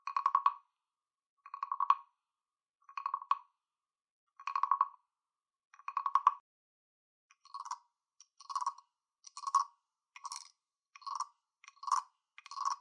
FROG 变体 - 声音 - 淘声网 - 免费音效素材资源|视频游戏配乐下载
用TASCAM DR录制。关闭透视，单声道录制。
中风脊柱获得独特的声音。这种录音中使用的木制青蛙装饰是中等大小。